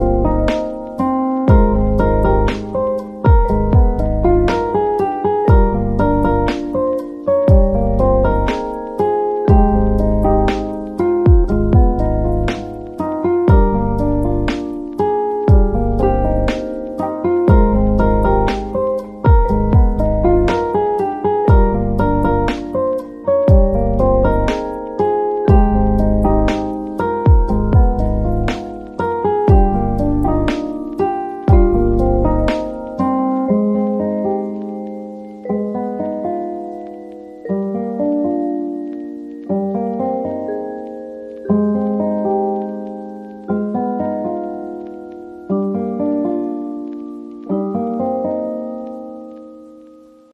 Lofi Chill